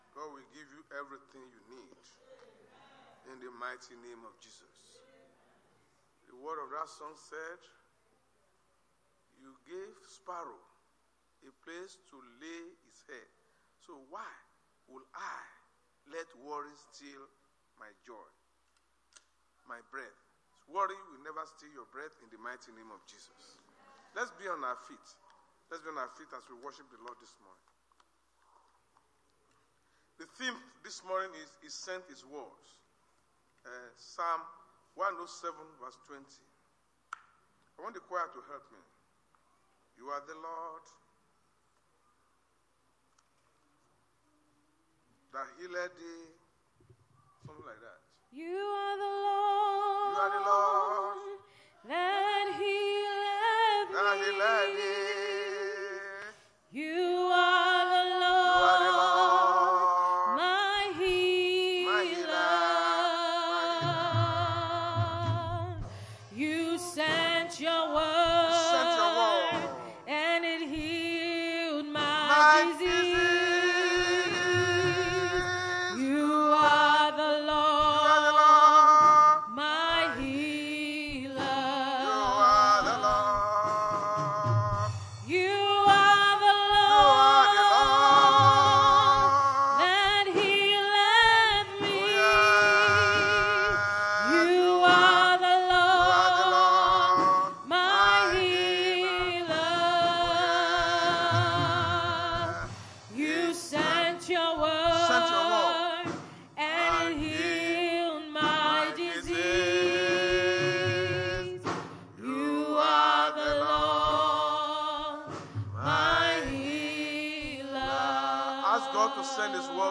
Sunday Sermon Podcast: He Sent His Word
Service Type: Sunday Church Service